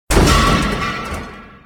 crash2.ogg